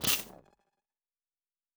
pgs/Assets/Audio/Sci-Fi Sounds/Electric/Spark 15.wav at 7452e70b8c5ad2f7daae623e1a952eb18c9caab4
Spark 15.wav